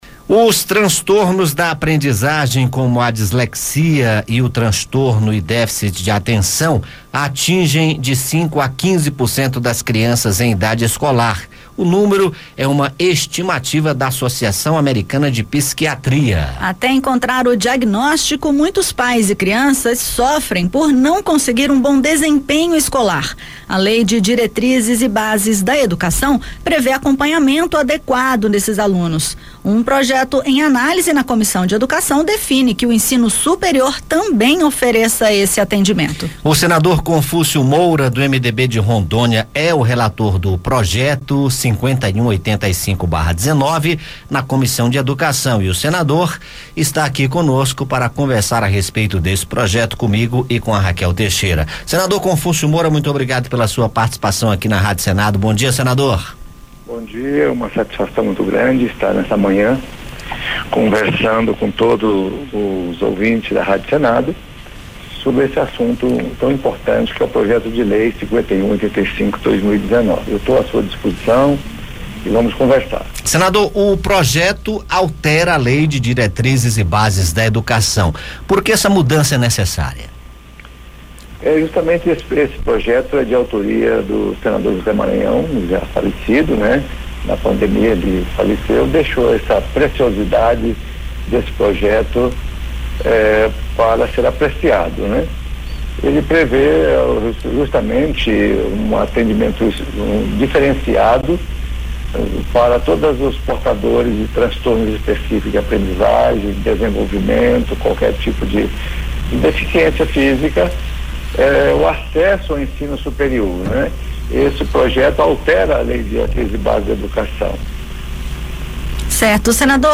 Rádio Senado Entrevista
Aqui você escuta as entrevistas feitas pela equipe da Rádio Senado sobre os assuntos que mobilizam o país e sobre as propostas que estão sendo discutidas no Parlamento.